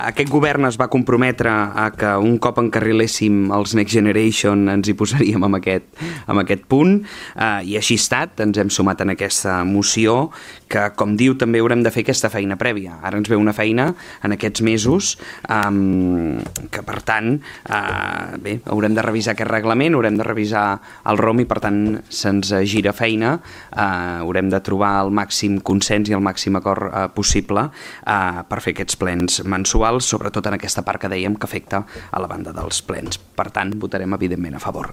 El regidor de Junts i portaveu del govern, Josep Grima, ha defensat que la proposta s’ha pogut abordar ara que “els fons Next Generation estan encarrilats”